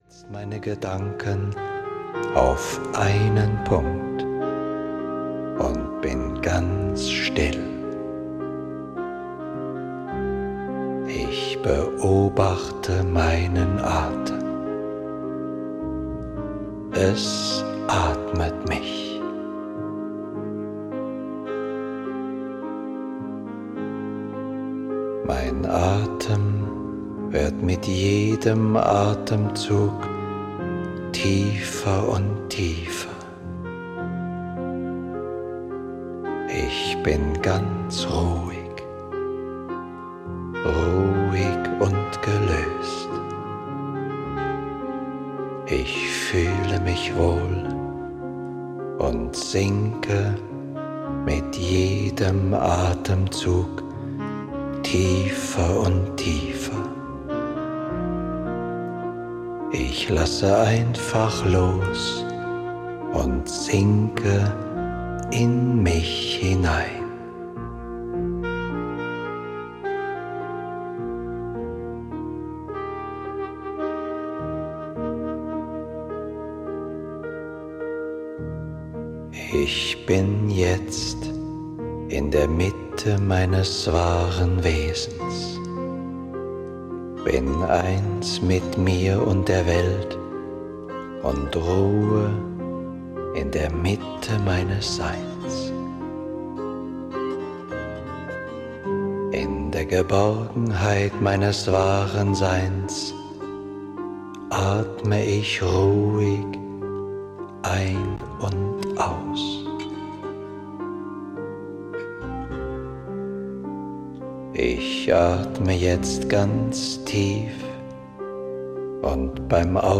Hilfe zur Selbsthilfe: Magersucht - Hörbuch